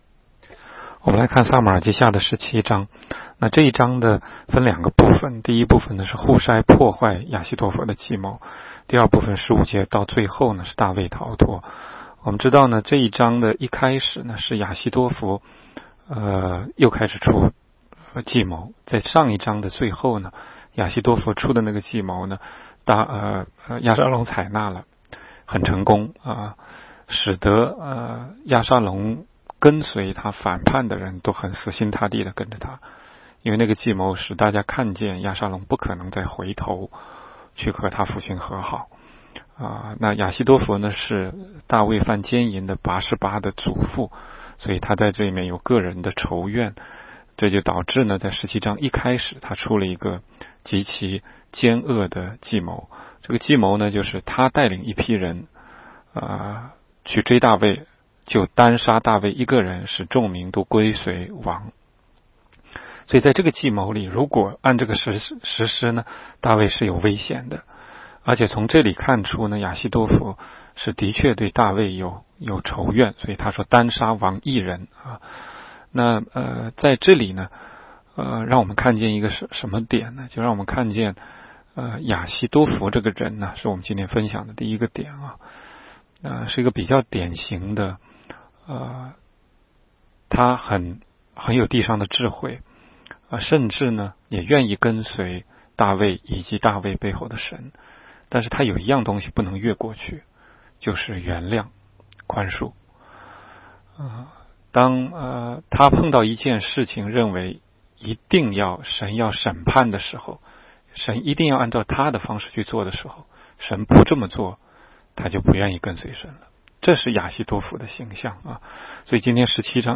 16街讲道录音 - 每日读经-《撒母耳记下》17章